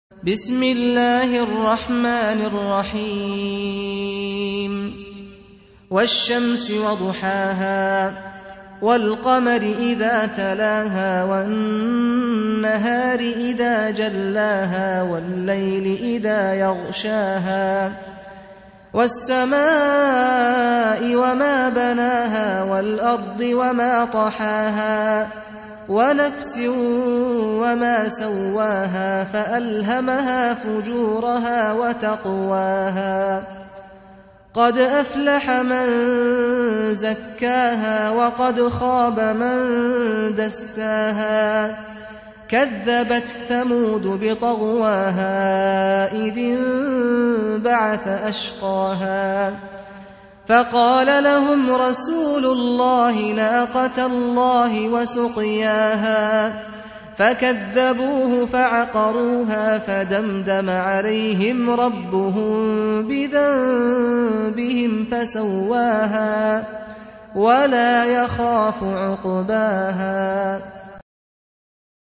91. Surah Ash-Shams سورة الشمس Audio Quran Tarteel Recitation
Surah Repeating تكرار السورة Download Surah حمّل السورة Reciting Murattalah Audio for 91.